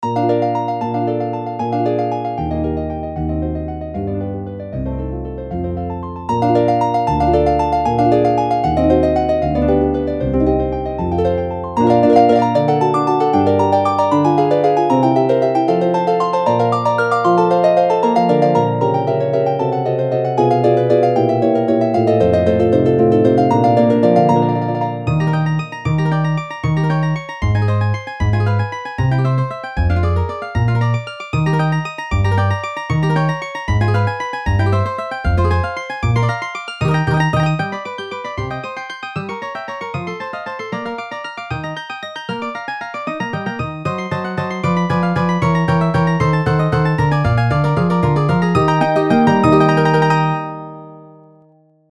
I am updating some of my early MIDI files, mostly in the category of stereo effects.